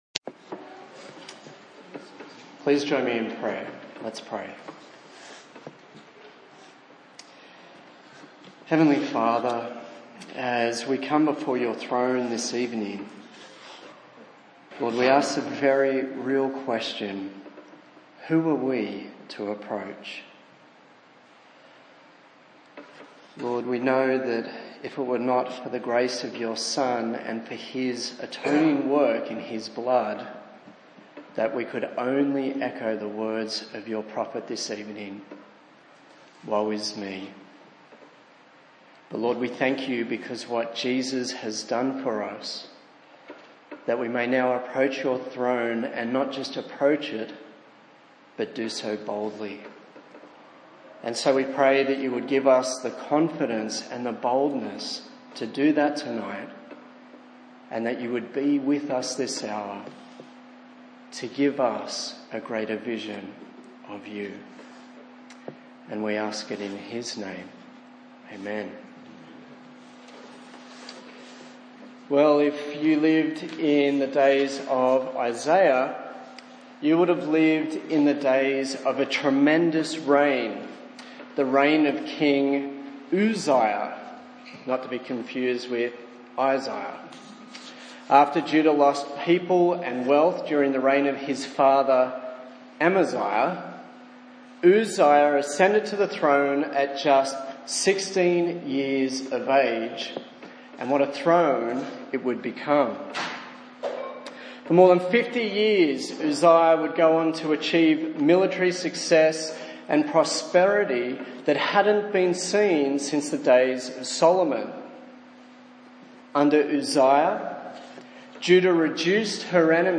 A sermon on the series from Isaiah